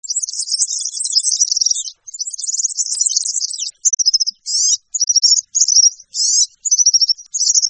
En cliquant ici vous entendrez le chant du Serin Cini.